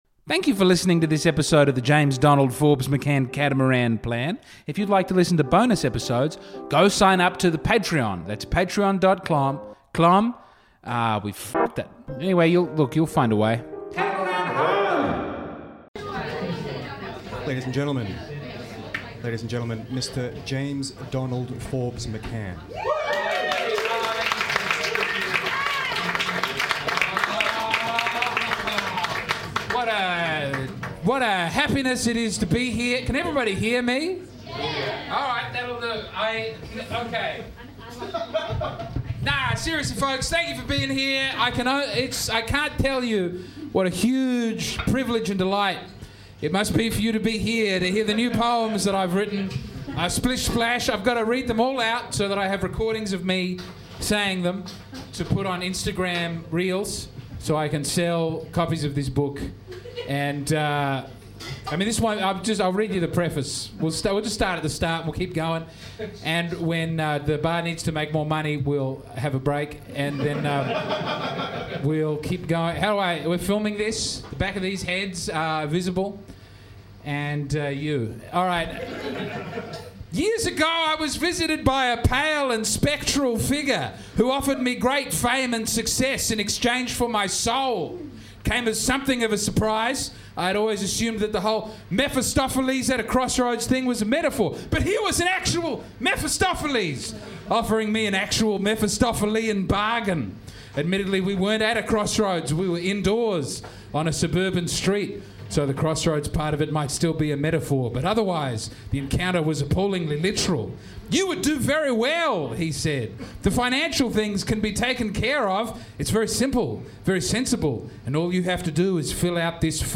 Splish Splash - Full-Blown Poetry Recital